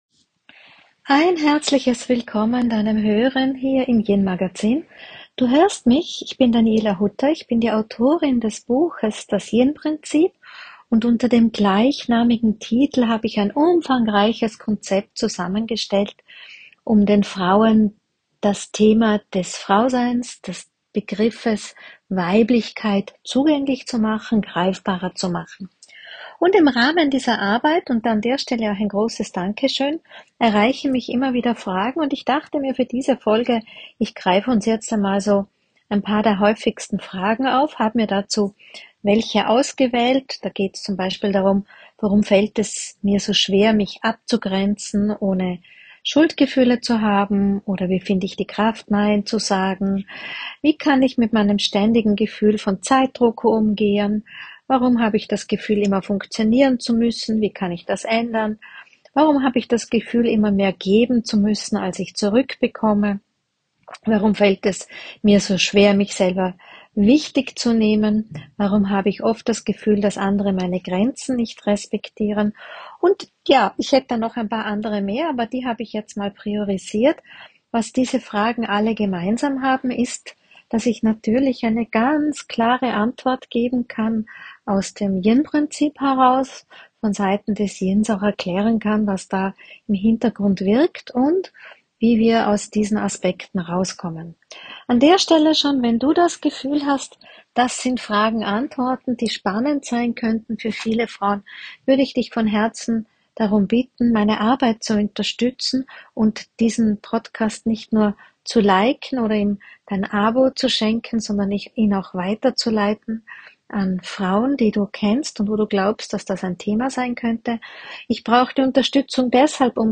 In diesem Podcast erhälst du Impulse und Antworten auf die Fragen rund um die Themen von FrauSein, Weiblichkeit und weibliche Energien. Es erwarten dich Interviews und Eindrücke aus meiner Gedankenwelt.